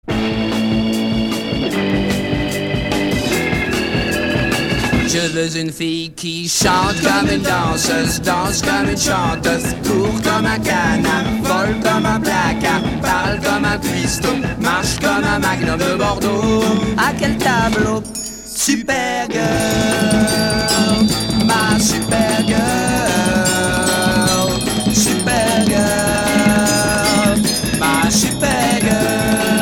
Pop psychédélique Deuxième 45t